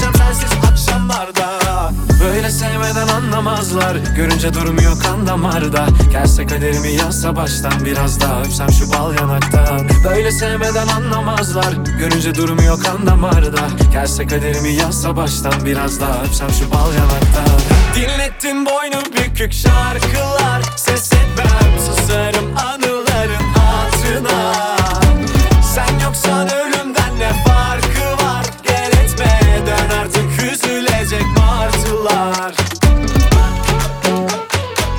Жанр: Поп / Турецкая поп-музыка